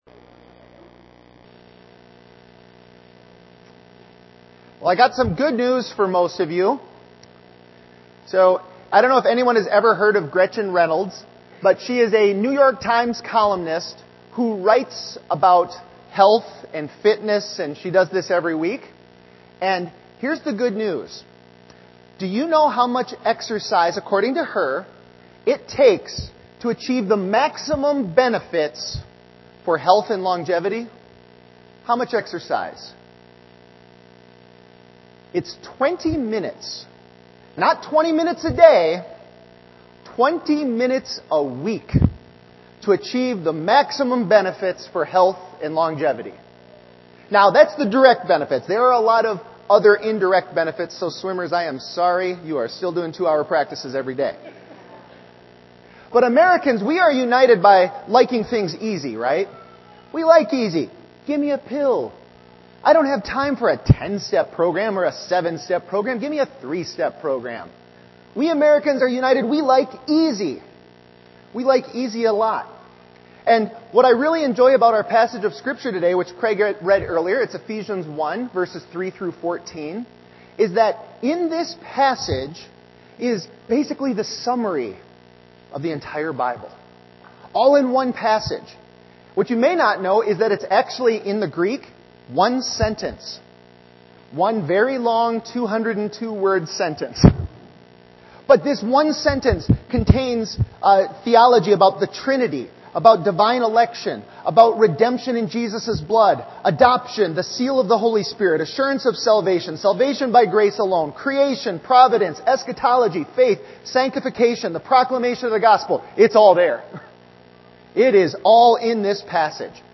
Ephesians 1:15-23 Service Type: Sunday Worship